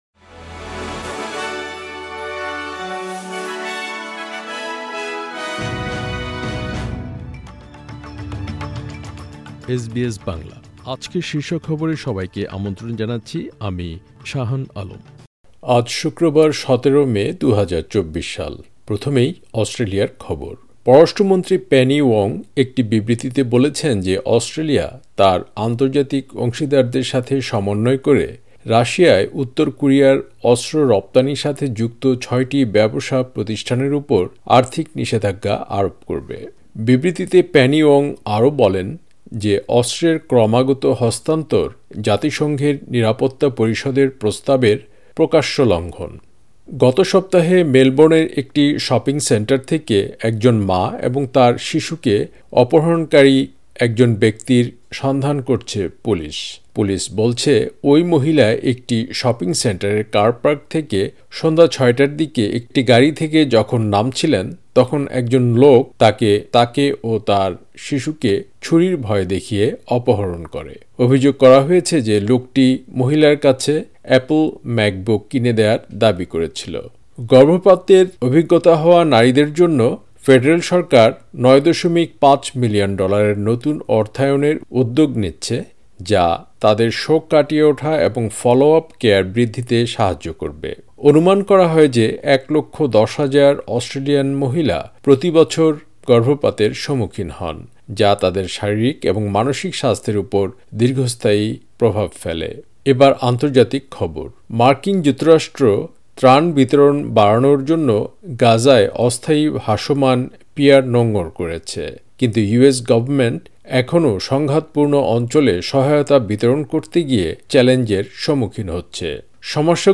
এসবিএস বাংলা শীর্ষ খবর: ১৭ মে, ২০২৪